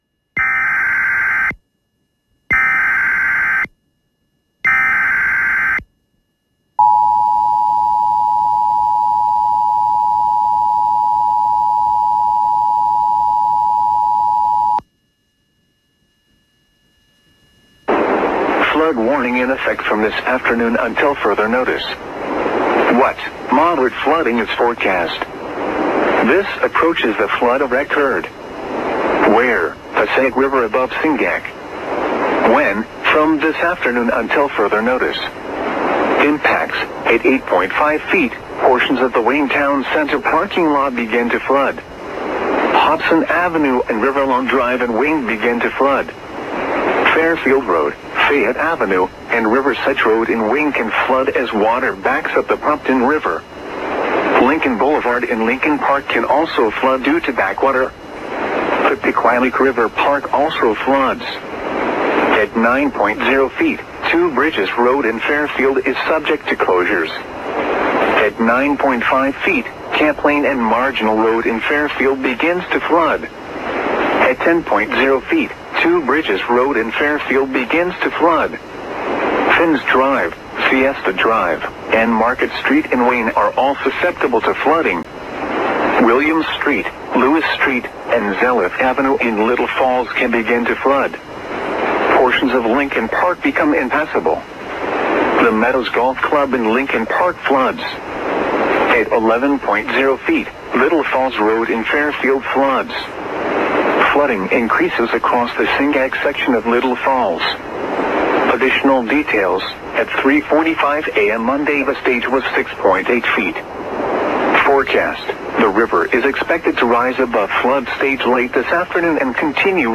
File:Flood warning.ogg
English: This file represents the alert of a flood warning.
Author NOAA Weather Radio
{{{template}}} This file consists of actual or simulated versions of: Emergency Alert System codes, the Emergency Alert System Attention Signal, and/or the Wireless Emergency Alerts Attention Signal.